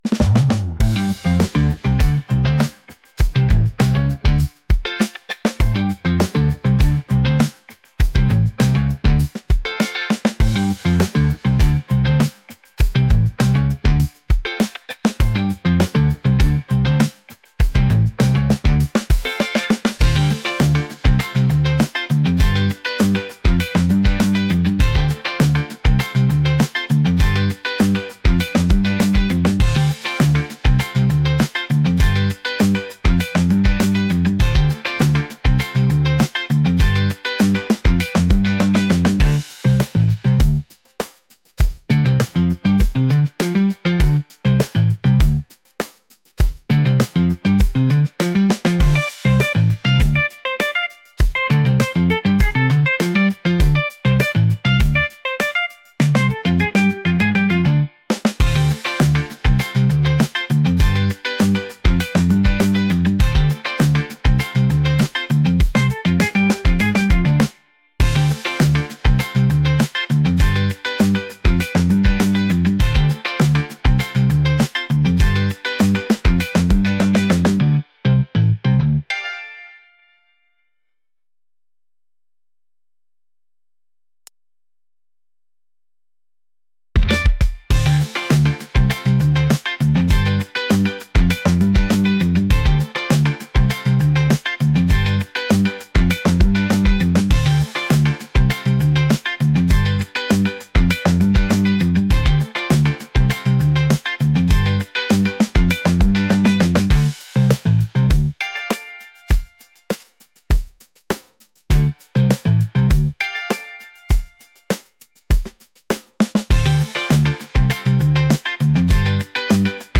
groovy | funk | energetic